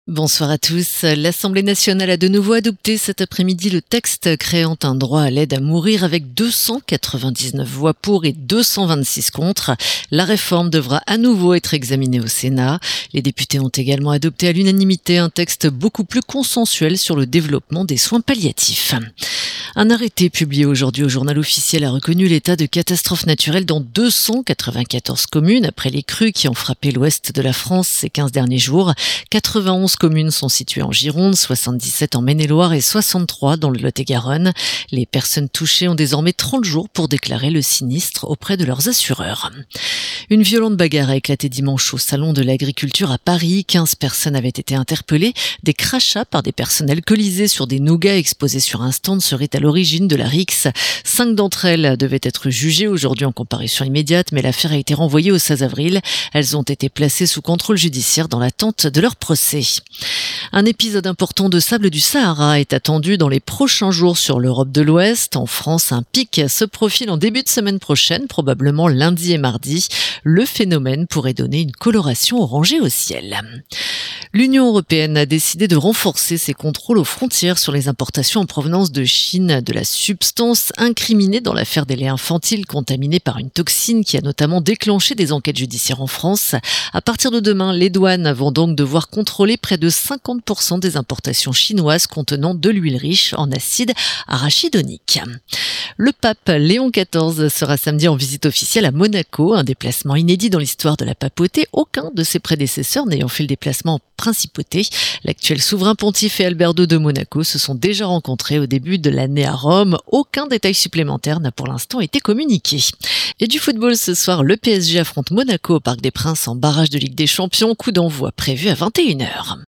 Flash infos 25/02/2026